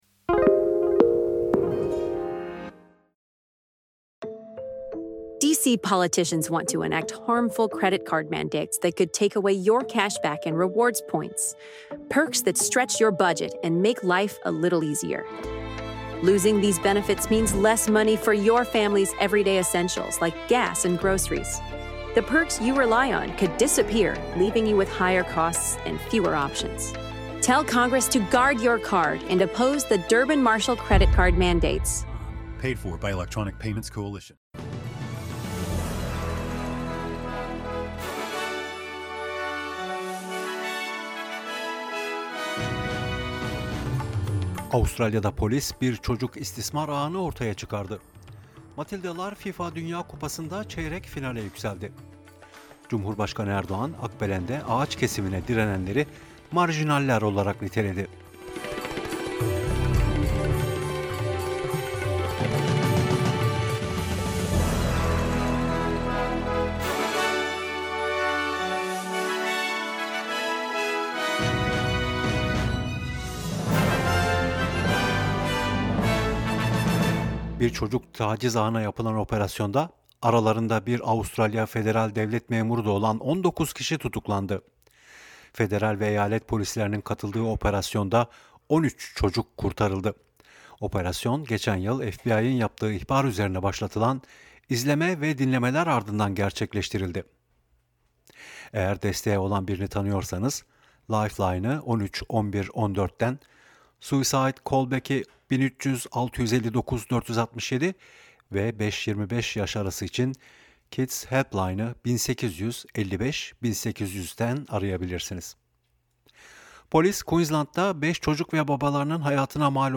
SBS Türkçe Haber Bülteni